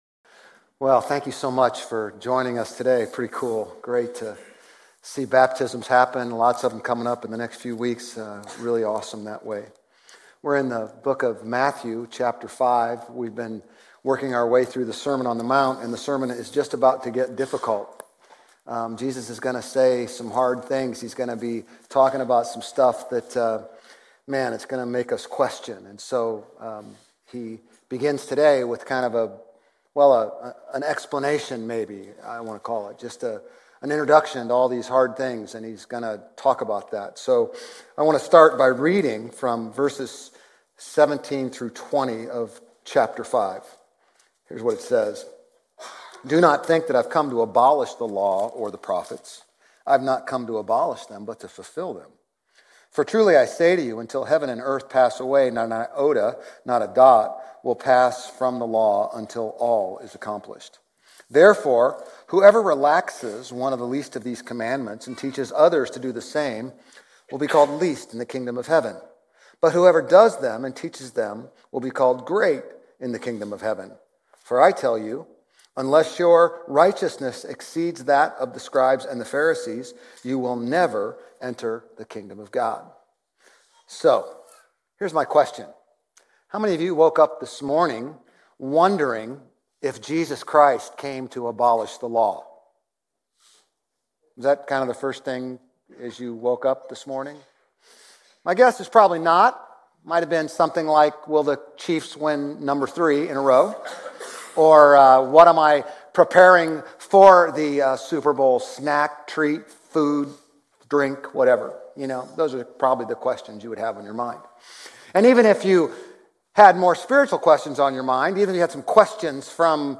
Grace Community Church Old Jacksonville Campus Sermons 2_9 Old Jacksonville Campus Feb 09 2025 | 00:33:46 1x 00:00 / 00:33:46 Subscribe Share RSS Feed Share Link Embed